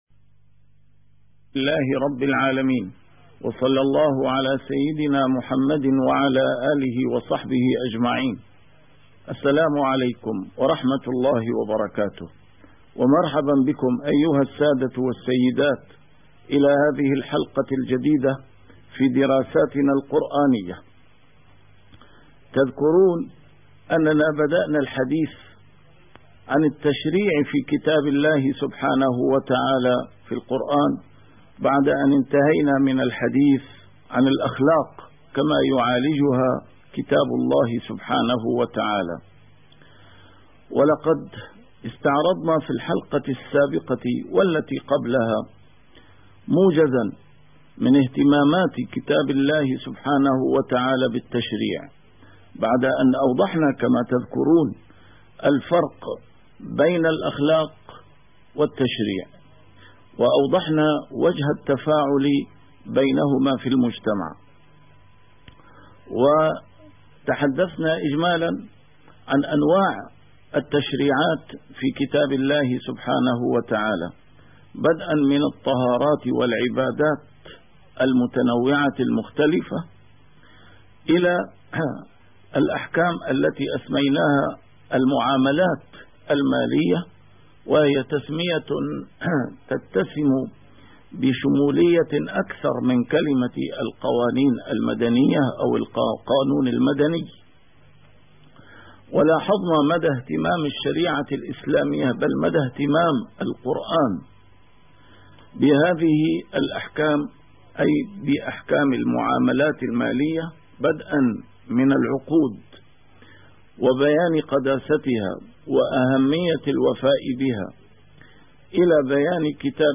A MARTYR SCHOLAR: IMAM MUHAMMAD SAEED RAMADAN AL-BOUTI - الدروس العلمية - دراسات قرآنية - الأحكام التشريعية في كتاب الله عز وجل